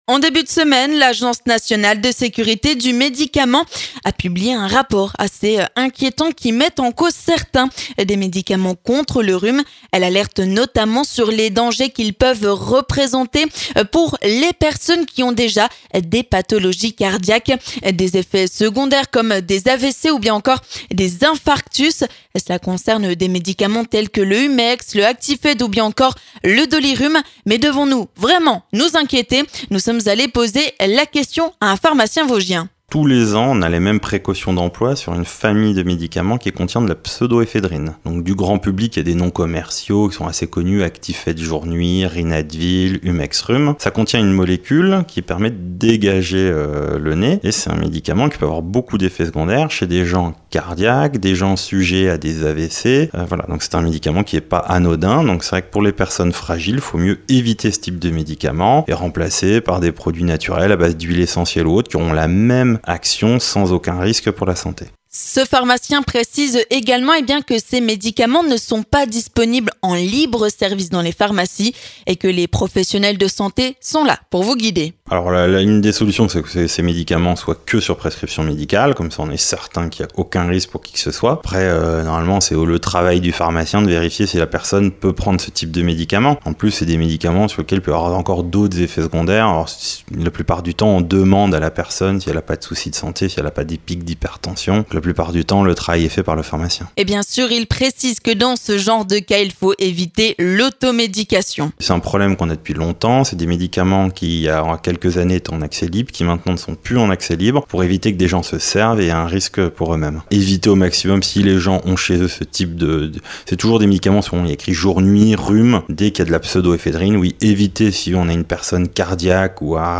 Mais face à cela, un pharmacien vosgien se veut rassurant !